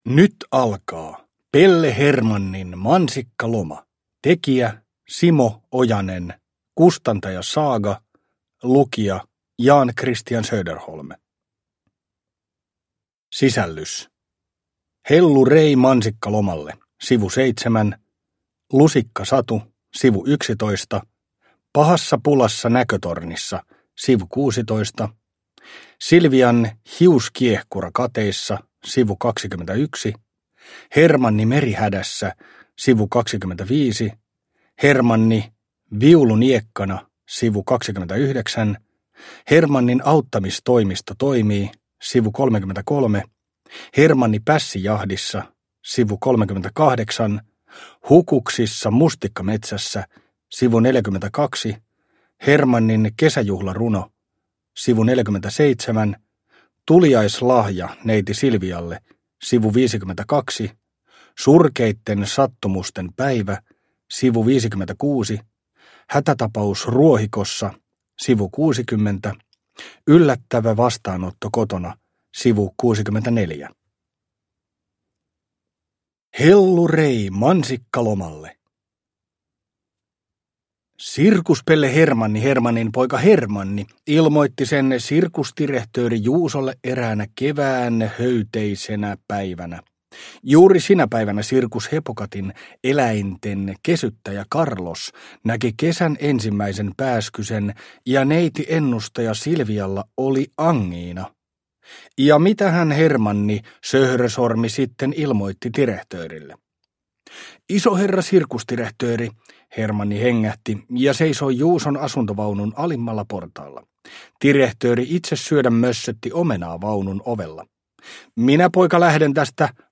Pelle Hermannin mansikkaloma – Ljudbok – Laddas ner